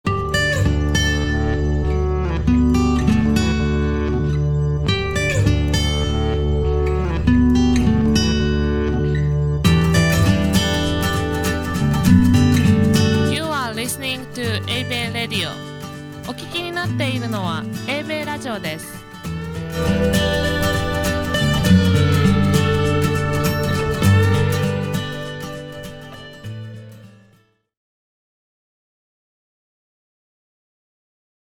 About Eibei Radio: Station ID
eibei-radio-short-id.mp3